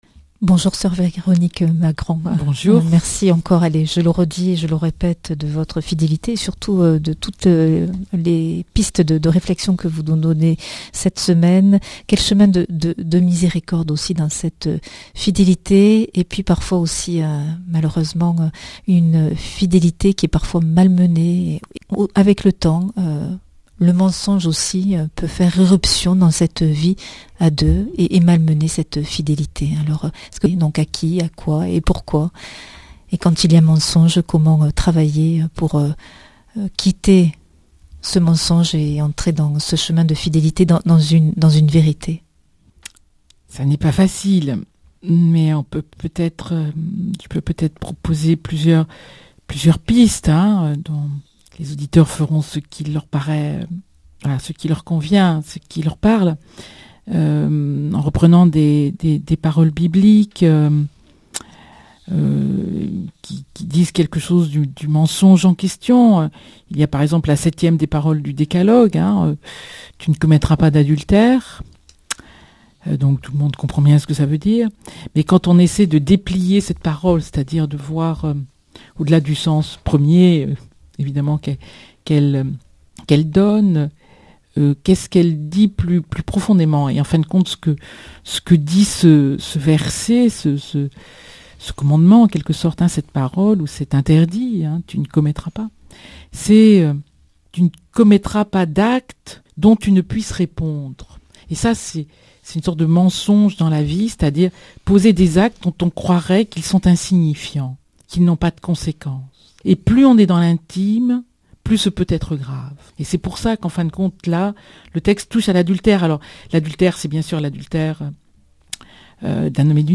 Invitée , Soeur Véronique Margron
Speech